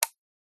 Звуки переключателя, выключателя
Включение или отключение переключателя света или розетки 15